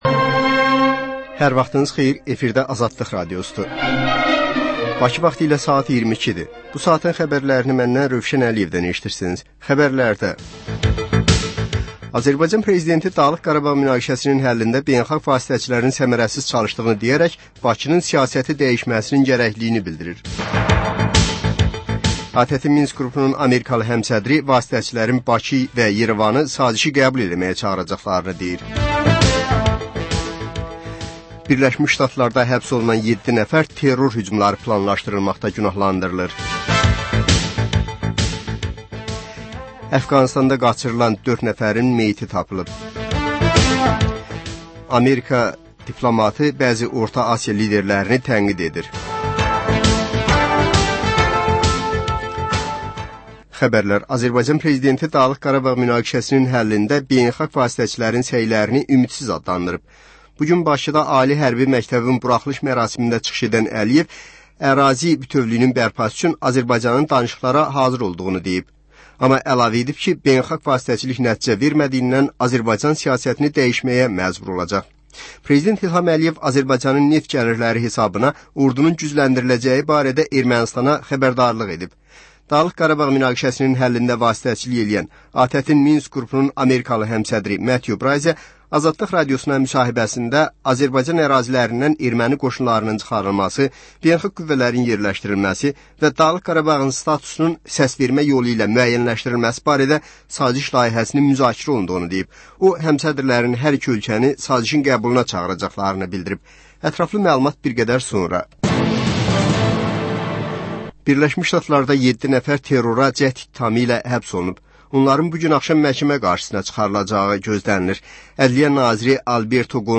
Xəbərlər, reportajlar, müsahibələr. Və sonda: Qlobus: Xaricdə yaşayan azərbaycanlılar barədə xüsusi veriliş.